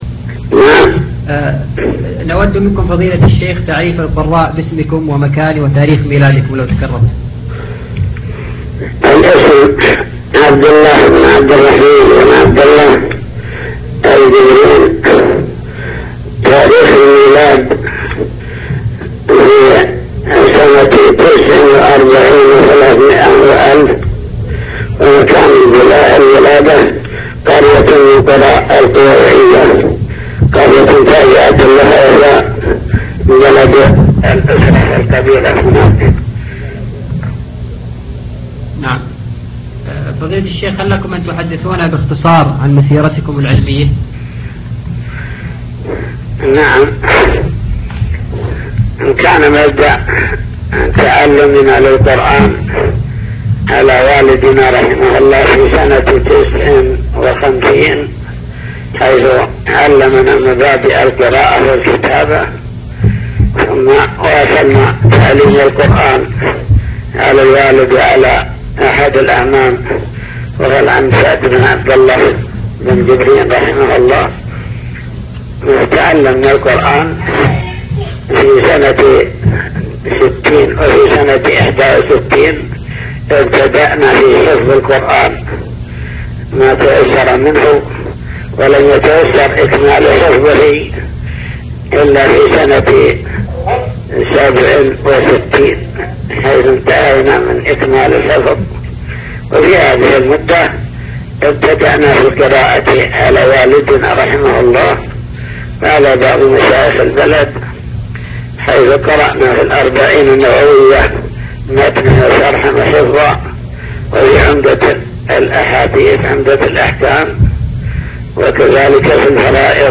تسجيلات - لقاءات